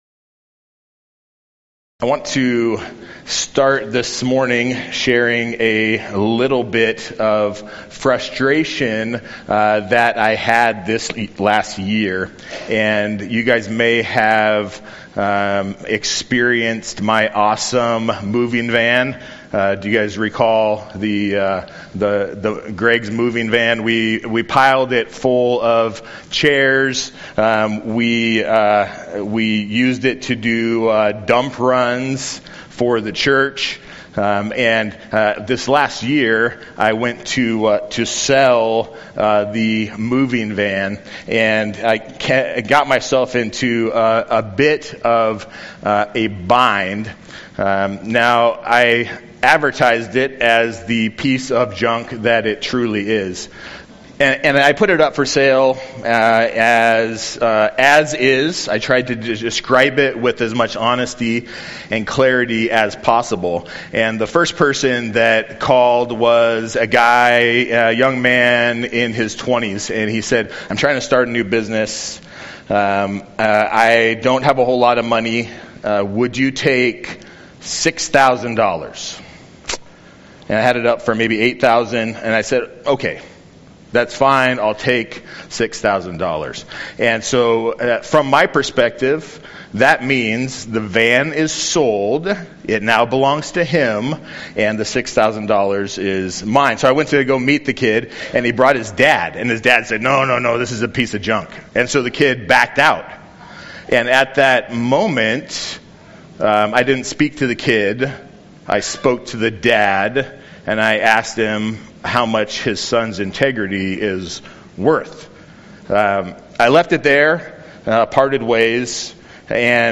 Sermon Text: Matthew 5:31-37 Main Point: To be like Jesus is to be trustworthy to the core…